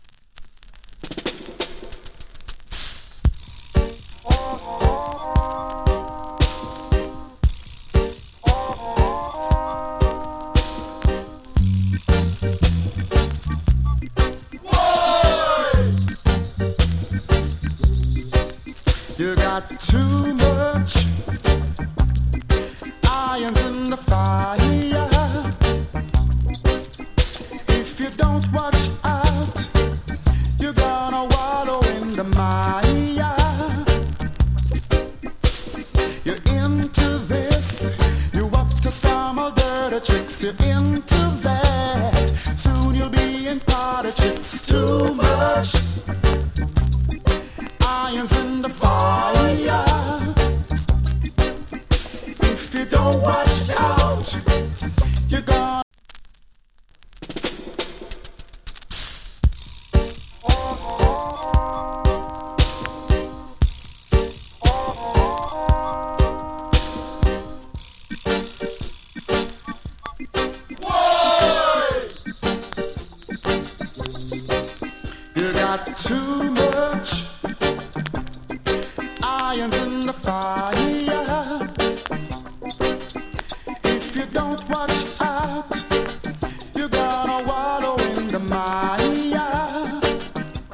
プレスによる周期的なジリノイズ有り。チリ、パチノイズも少し有り。
'80 RARE ROOTS VOCAL !